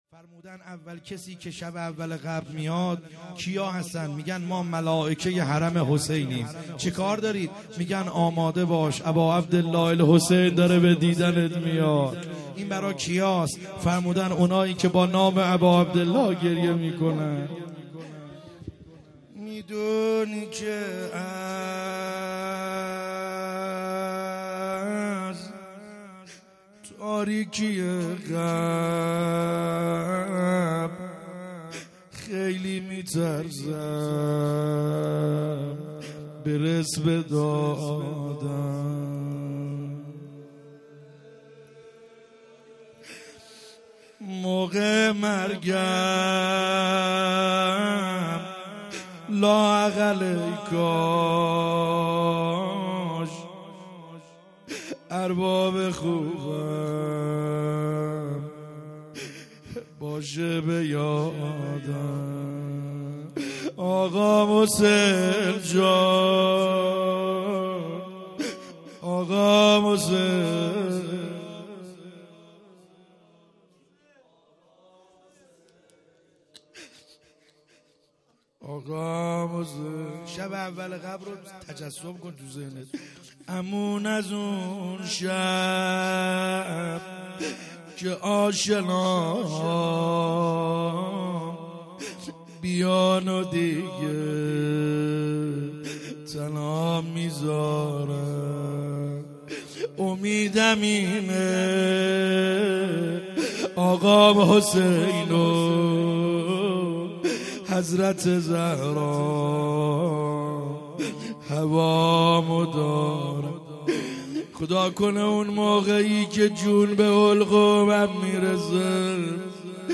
خیمه گاه - بیرق معظم محبین حضرت صاحب الزمان(عج) - روضه ا امام حسین علیه السلام